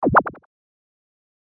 / F｜演出・アニメ・心理 / F-18 ｜Move コミカルな動き / ムニュ2
プニュ